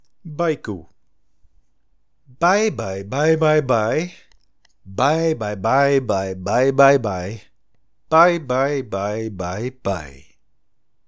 Poëzie